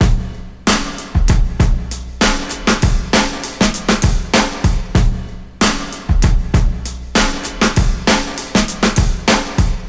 • 97 Bpm Drum Beat E Key.wav
Free breakbeat - kick tuned to the E note. Loudest frequency: 1765Hz
97-bpm-drum-beat-e-key-Trt.wav